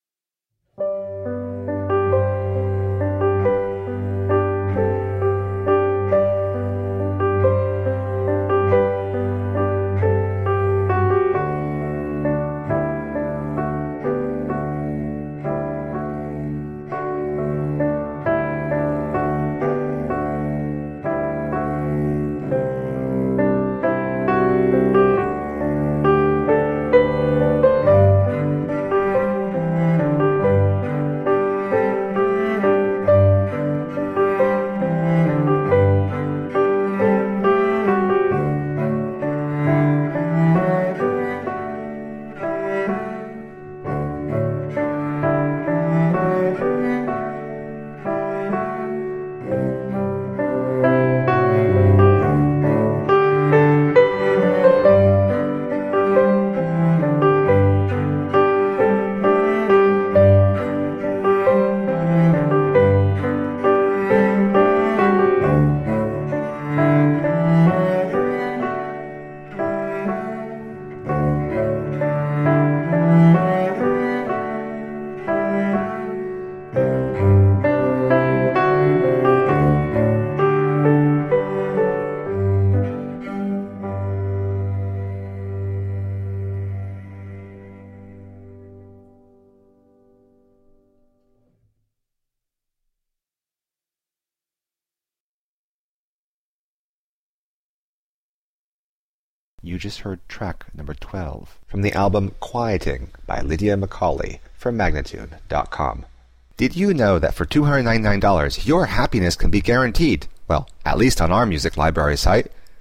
Intriguing piano compositions with dynamic accompaniment.
Tagged as: New Age, Folk, Instrumental